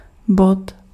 Ääntäminen
France (Paris): IPA: [æ̃ pwæ̃]
Quebec: IPA: [pwɛ̃ɪ̃]